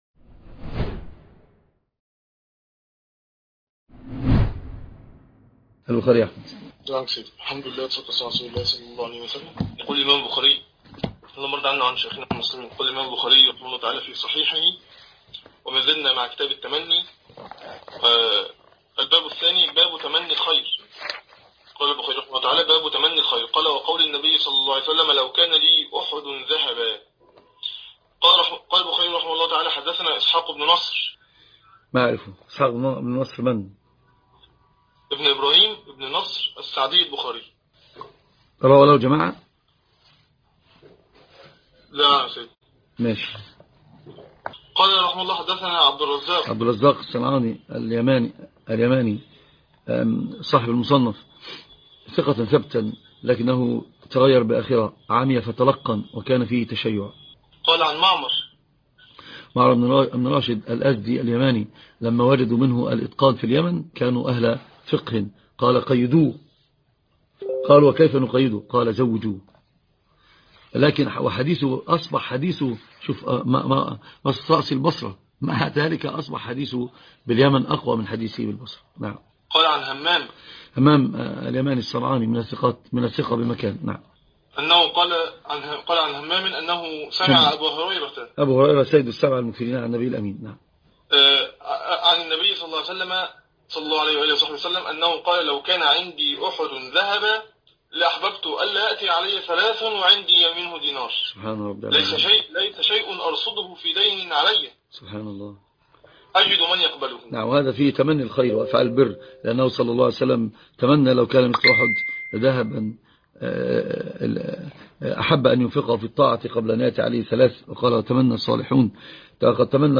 الحديث وعلومه     شرح كتب حديثية